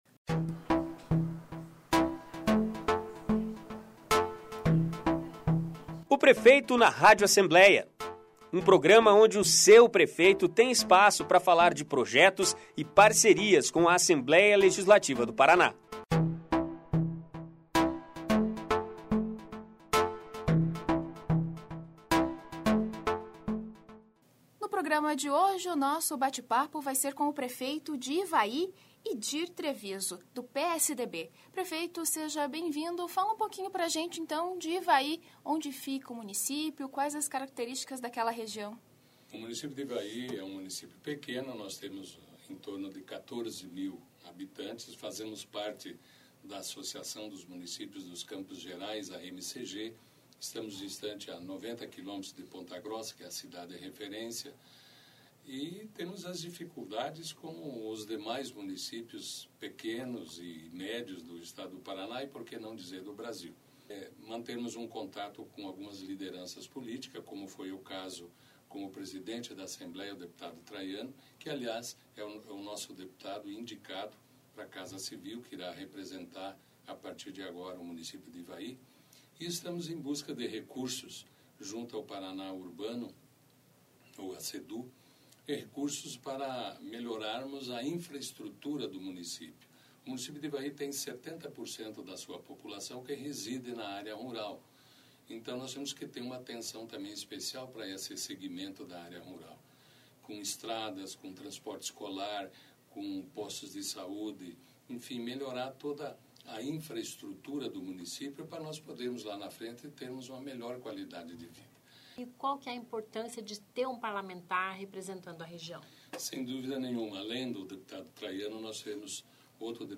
Idir Treviso, de Ivaí é o entrevistado esta semana no "Prefeito na Rádio Alep"